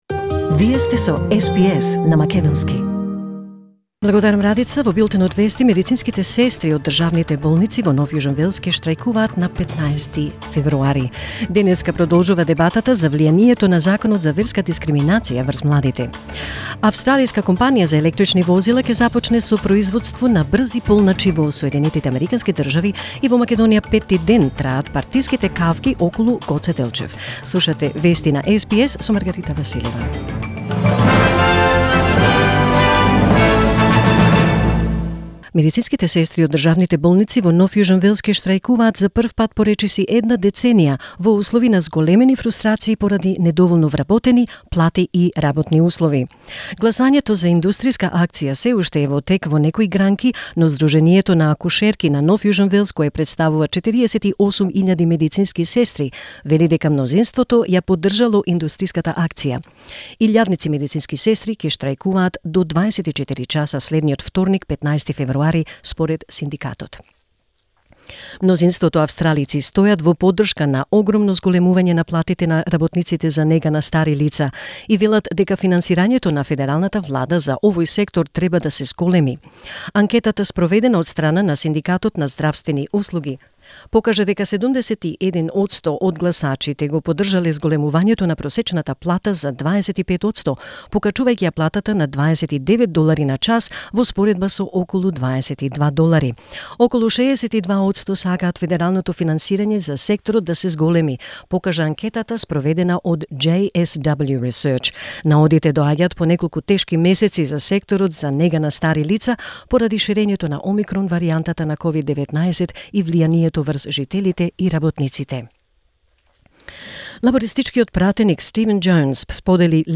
SBS News in Macedonian 9 February 2022